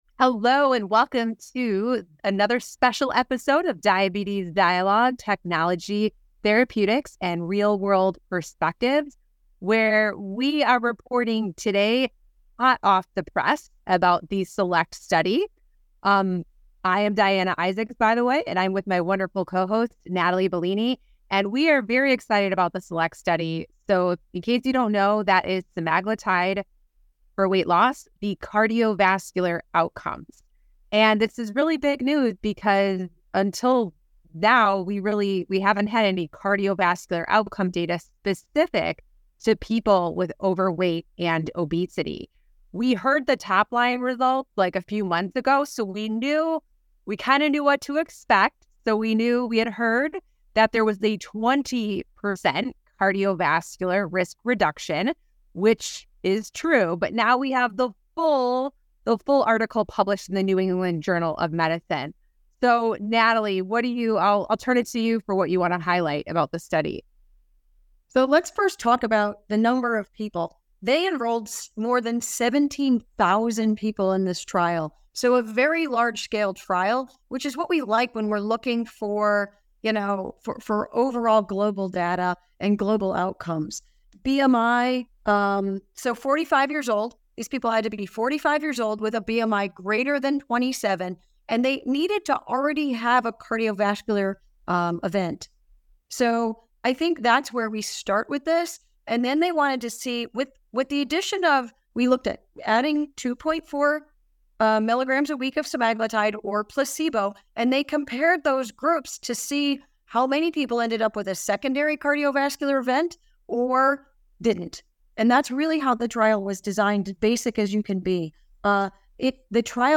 In this episode, hosts take a deep dive into the study’s results, including secondary endpoints, and what it means for the care of patients with overweight or obesity and preexisting cardiovascular disease in real-world settings.